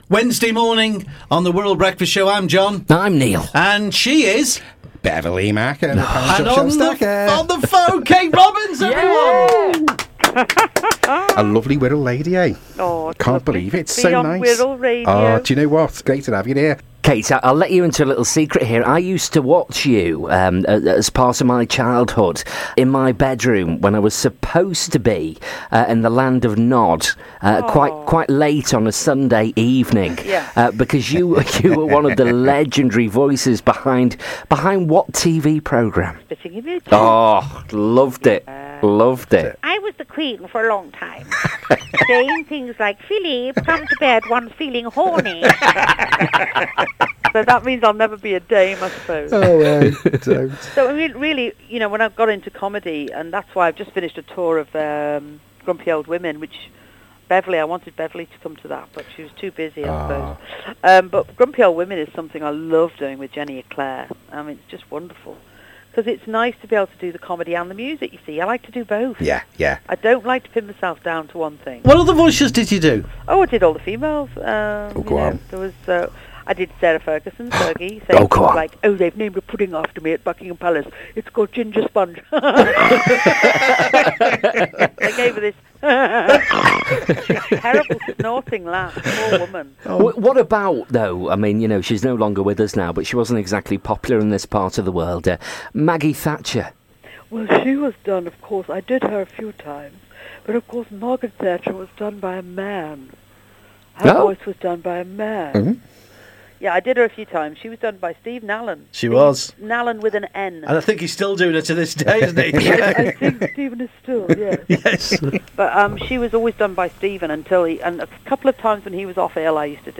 part of the Wirral Radio Breakfast Show.